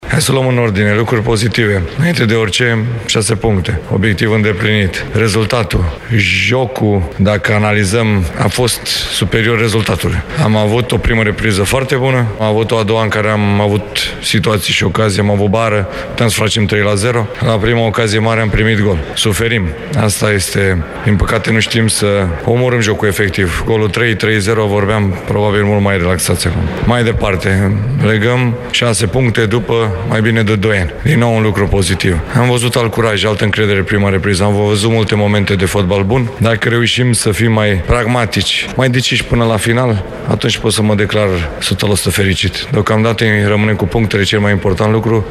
Selecționerul Edward Iordănescu a expus, pe puncte, concluziile după meciul cu Belarus: